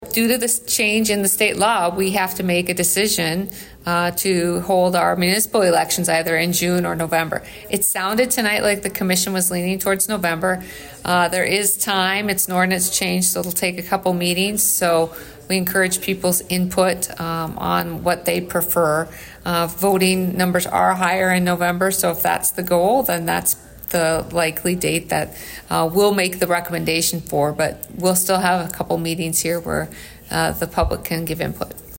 City Manager, Amy Leon, says that the city will no longer will be able to hold elections in April and will have to move them to either June or November.